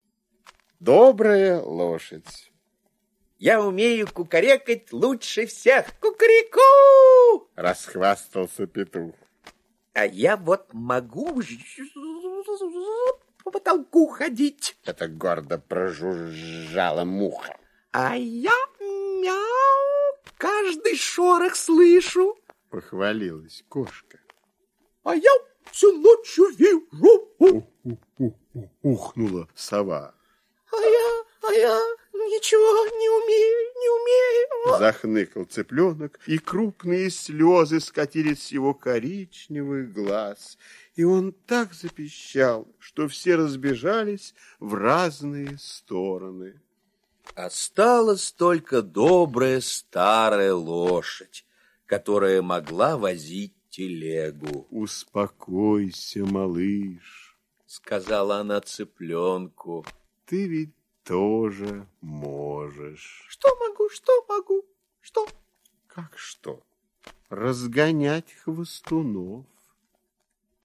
Слушайте Добрая лошадь - аудиосказка Пляцковского М.С. Сказка про то, как однажды собрались животные и стали хвастаться: в каком деле они самые лучшие.